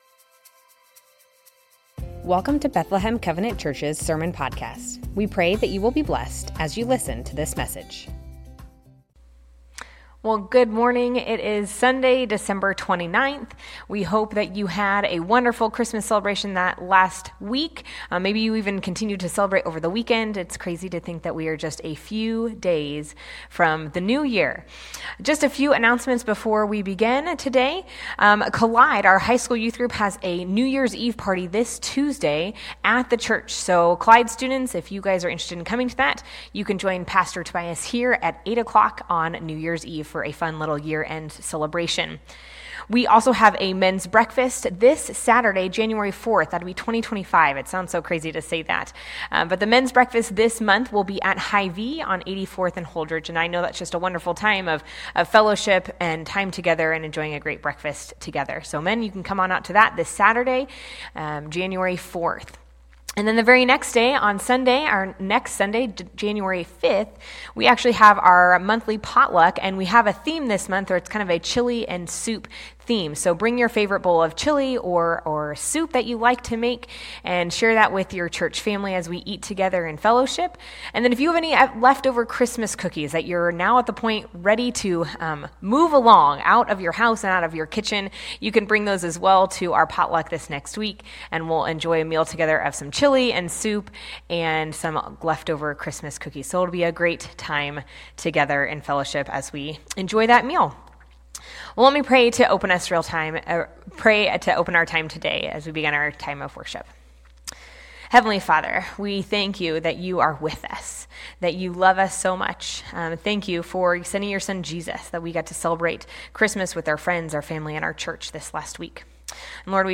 Bethlehem Covenant Church Sermons Matthew 2:1-12 - The Wise Men Dec 29 2024 | 00:35:40 1x 00:00 / 00:35:40 Subscribe Share Spotify RSS Feed Share Link Embed